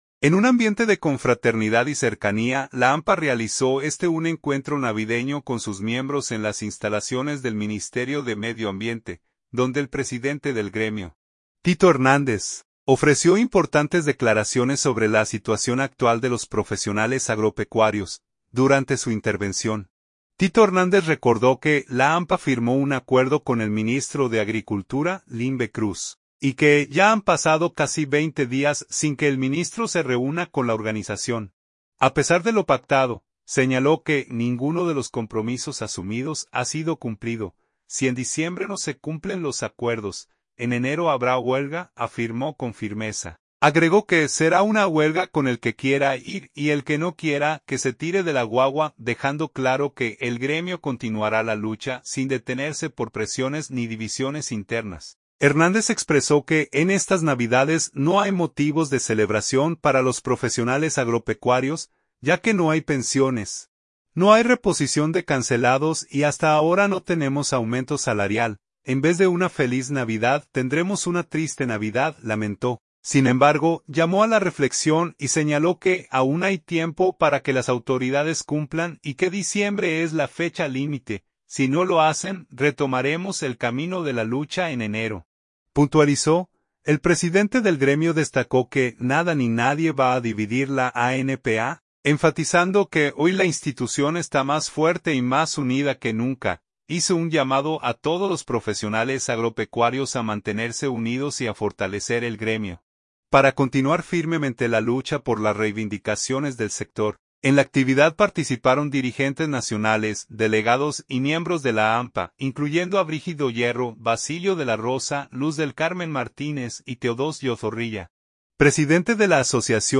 En un ambiente de confraternidad y cercanía, la ANPA realizó este un encuentro navideño con sus miembros en las instalaciones del Ministerio de Medio Ambiente